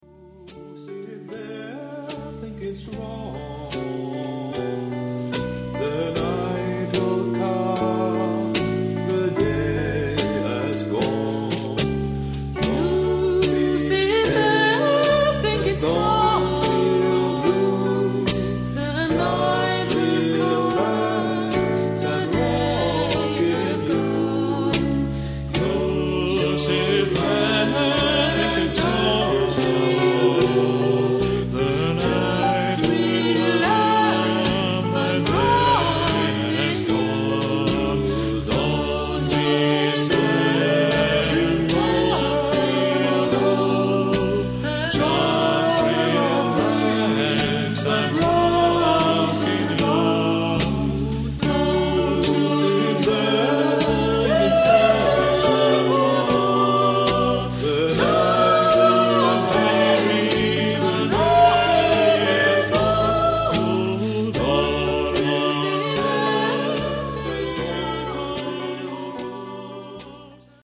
Genre-Stil-Form: Kanon ; Blues ; weltlich
Chorgattung:  (4 gleichstimmig Stimmen )
Tonart(en): D-Dur